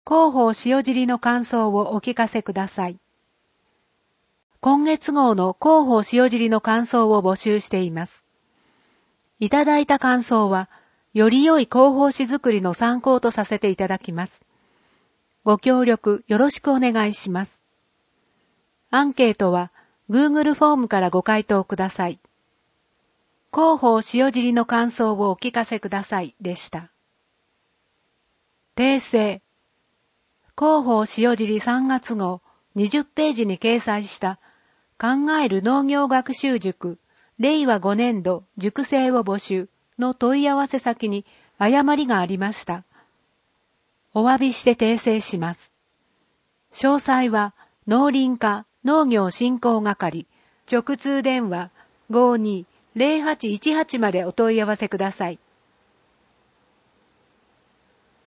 PDF版 テキスト版 声の広報